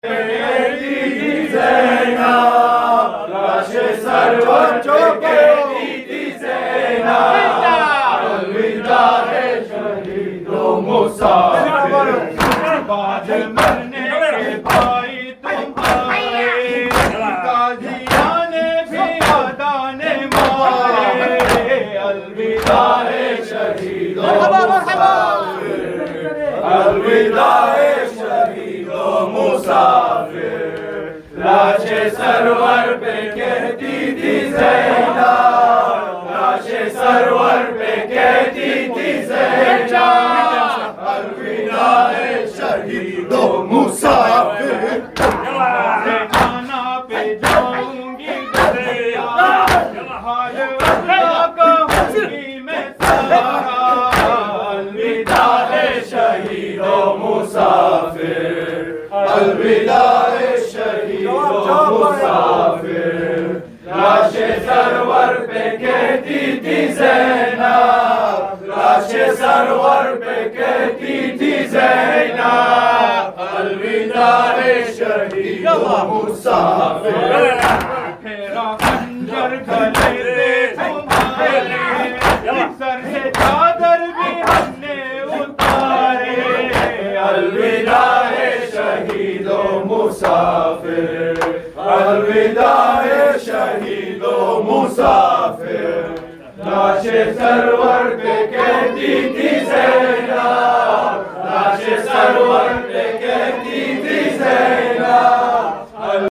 Chehlum / Arbaeen